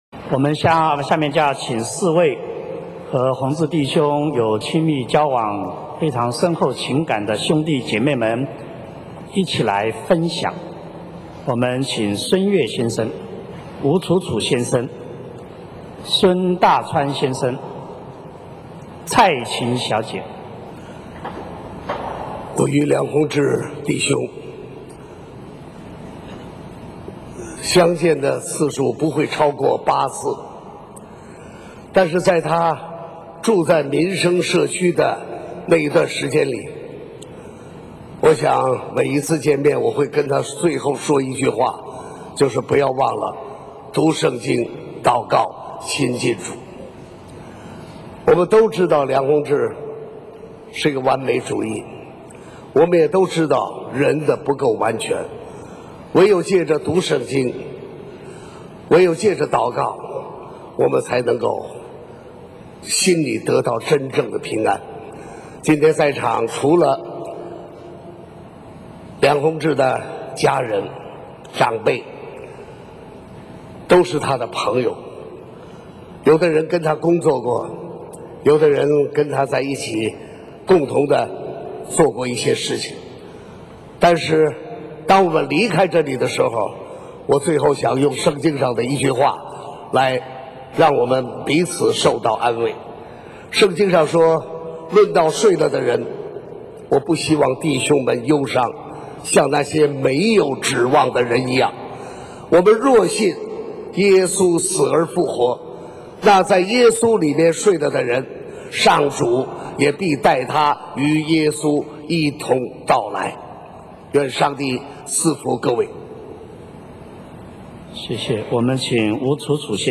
【追忆梁弘志】|第3集-殡葬弥撒〈圣祭礼仪二〉四位朋友的分享
四位梁弘志深厚交情的朋友分享他们的感言，他们分别是孙越、吴楚楚、孙大川、蔡琴。